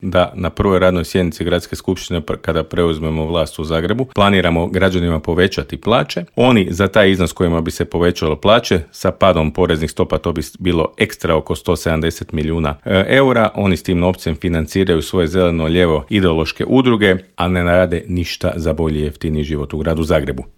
U metropoli po drugi mandat ide aktualni gradonačelnik Tomislav Tomašević, a gradonačelničkog kandidata HDZ-a Mislava Hermana ugostili smo u Intervjuu Media servisa.